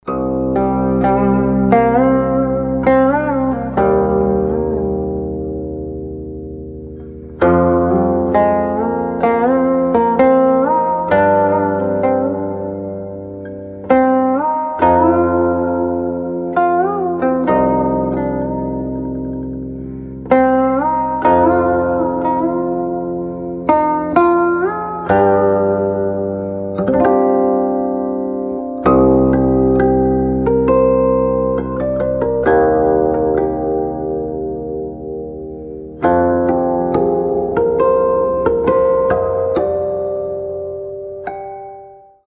Китайская мелодия - рингтон